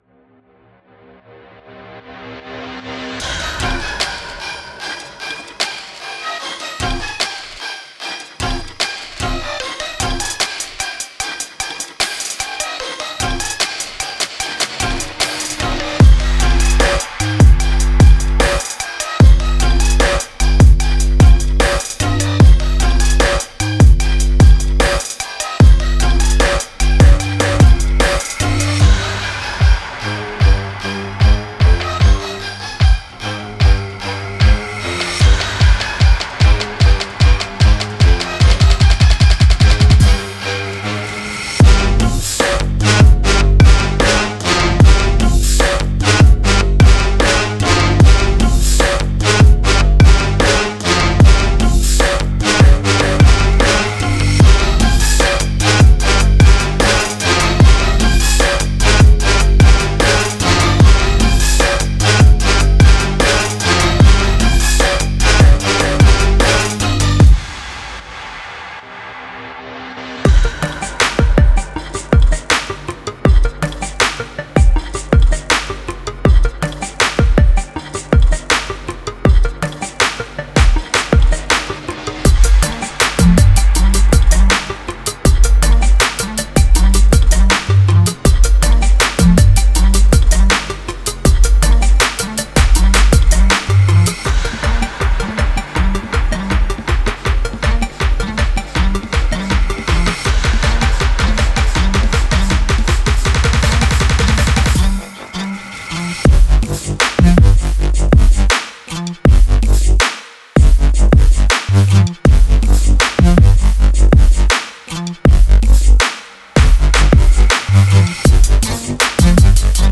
当然，任何出色的Trap乐器的基础都是低音。
从巨大的影响到嘈杂的竖琴，SFX会在最终确定作品的同时增加最后的专业感。
• 60 x巨大的鼓声
• 10个原子低音循环（+ MIDI）
• 26个Evil旋律循环（+ MIDI）